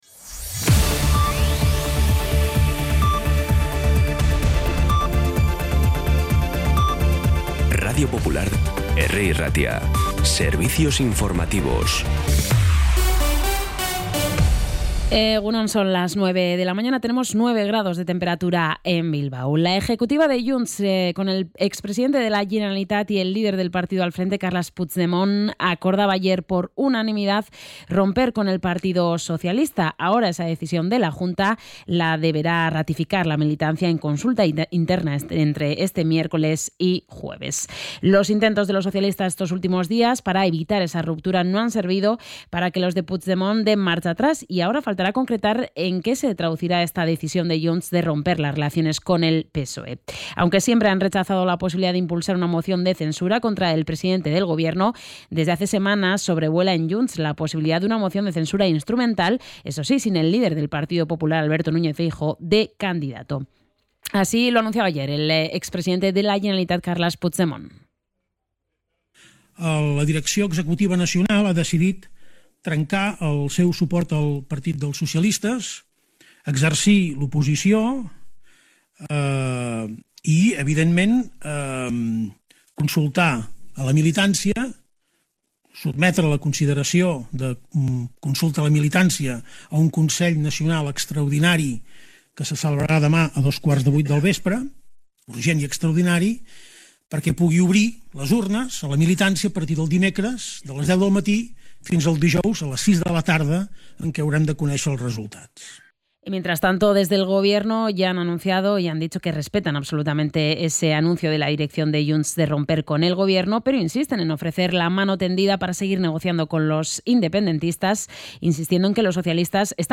Información y actualidad desde las 9 h de la mañana